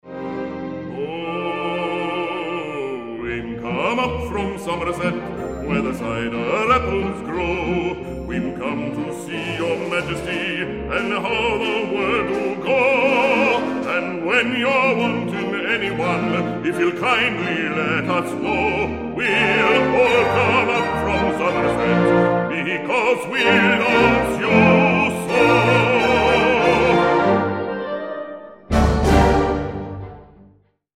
Bass Baritone